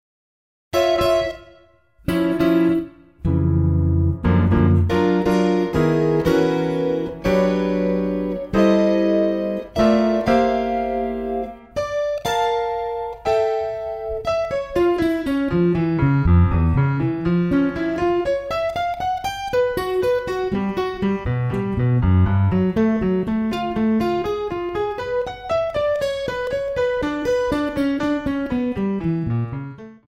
electric guitars & MIDI programming
drums
electric bass
piano
tenor saxophone